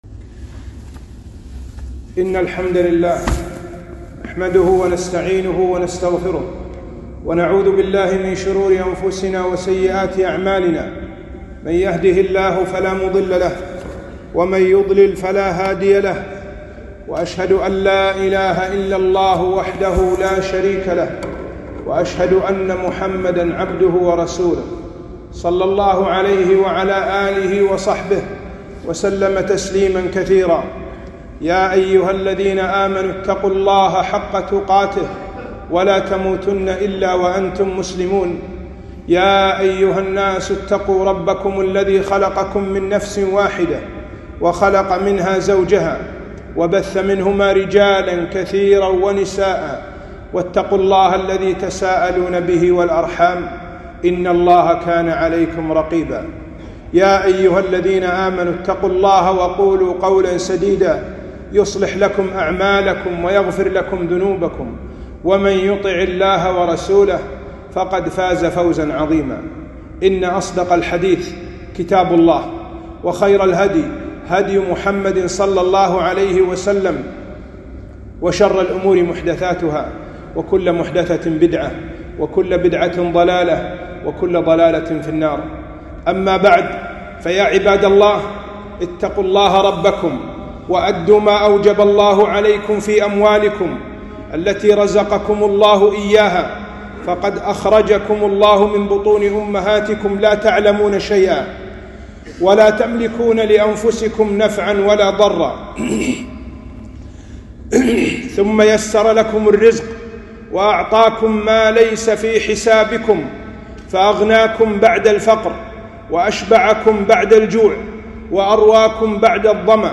خطبة - جوب زكاة المال